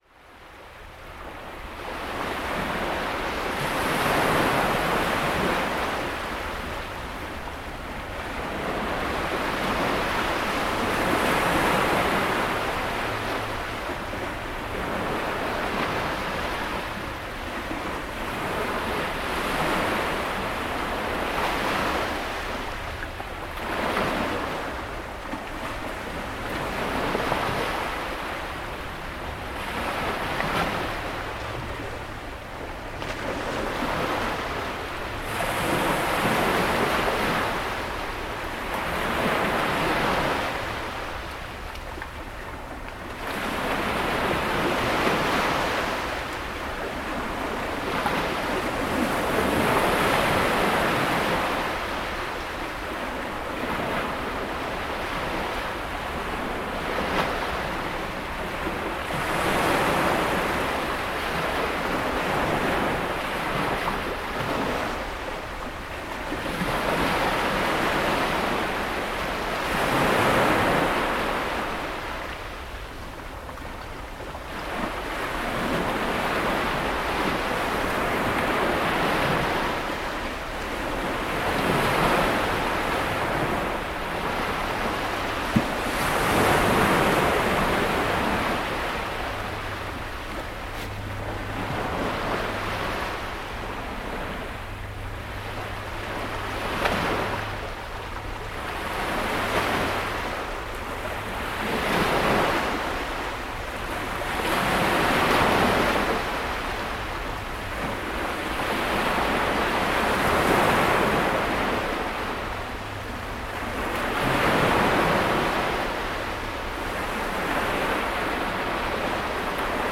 Sunset waves in Margate
Margate beach close to the Winter Gardens at sunset, June 2015.